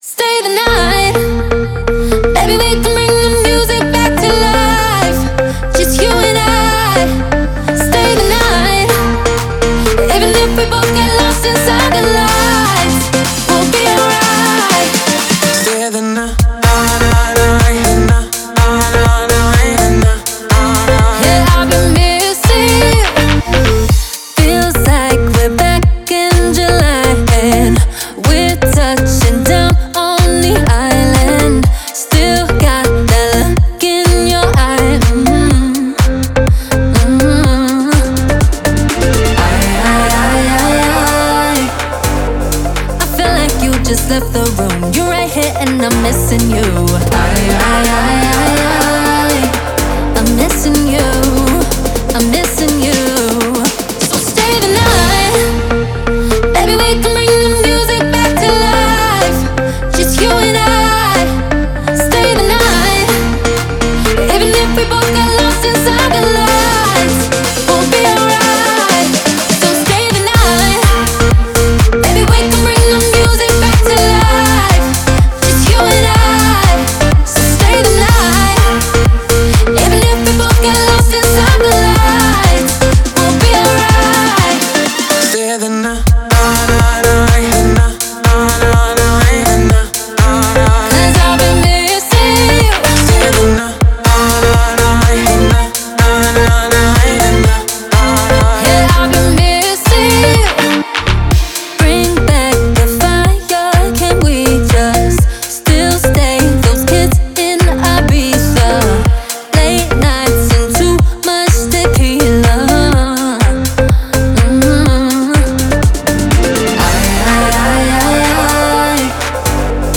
это яркая и энергичная танцевальная композиция в жанре EDM